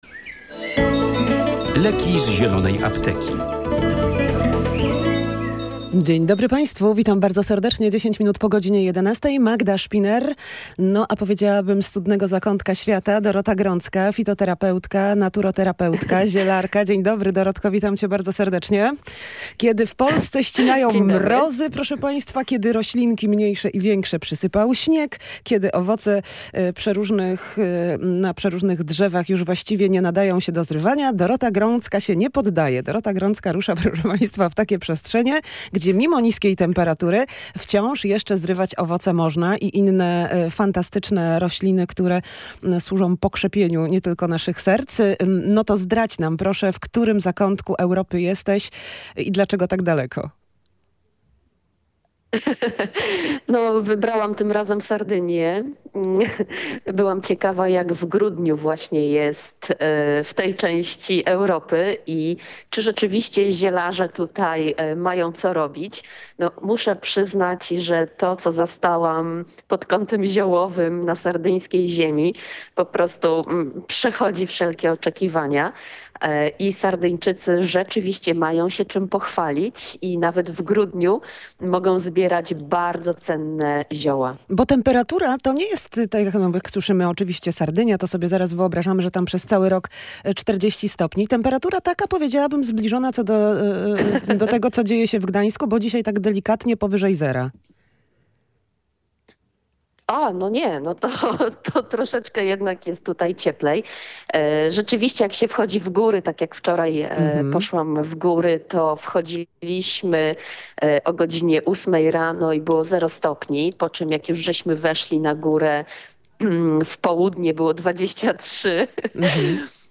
Jakie zatem cuda natury można znaleźć w grudniowe, chłodne dni? Zapraszamy do odsłuchania audycji.